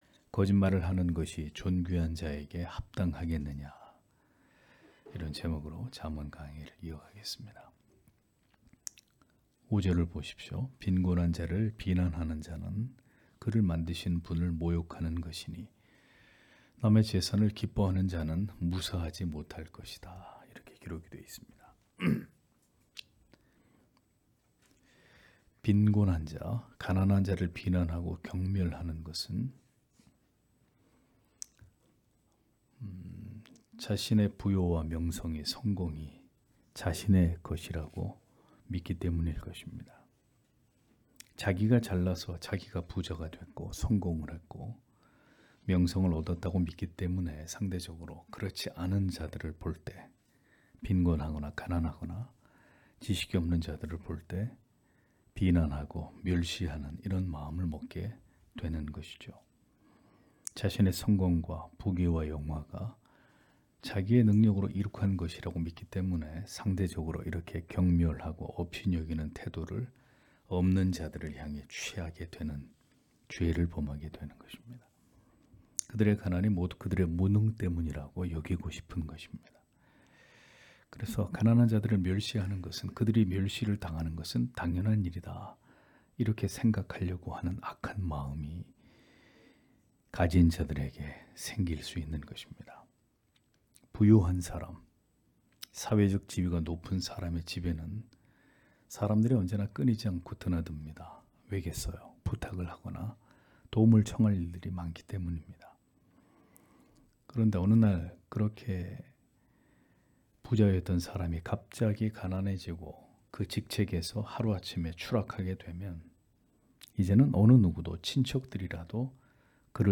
수요기도회 - [잠언 강해 99] 거짓말을 하는 것이 존귀한 자에게 합당하겠느냐 (잠 17장 5-8절)